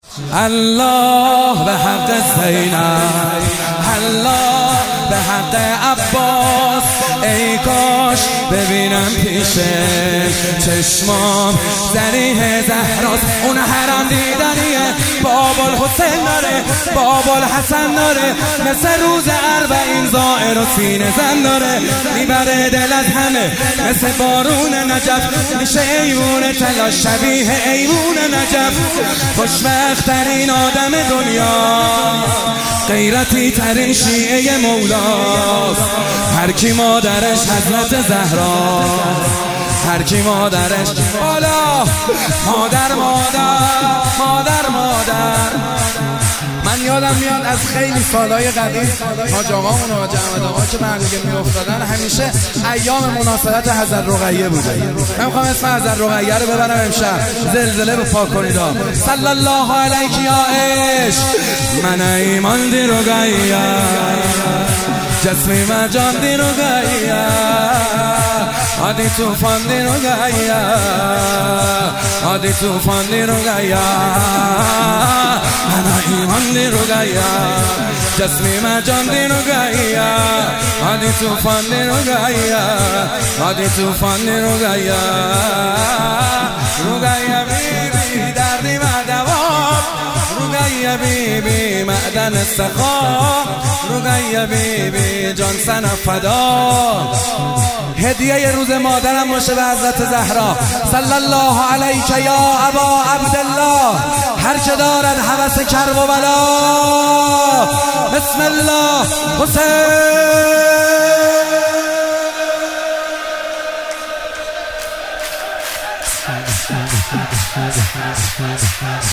ولادت حضرت زهرا(س)97 - سرود - الله به حق زینب
ولادت حضرت زهرا(س)